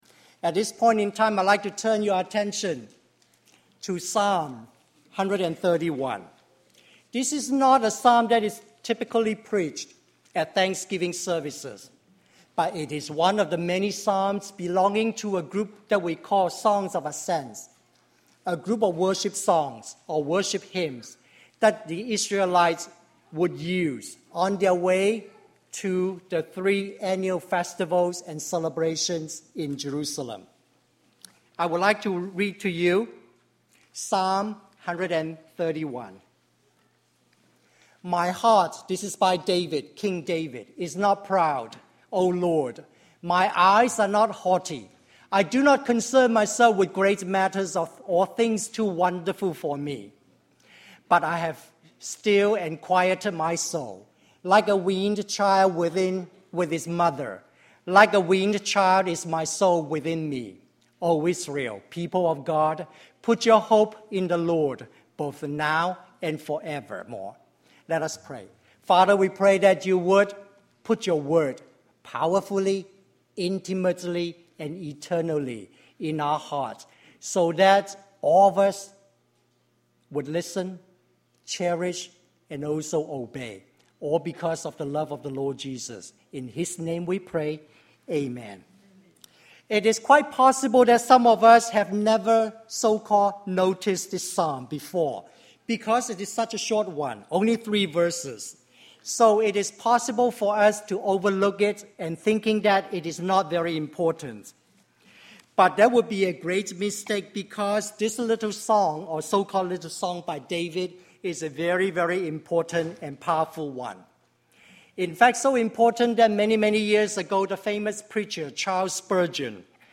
Joy-Filled Living in a Grumpy World: November 22, 2009 Sermon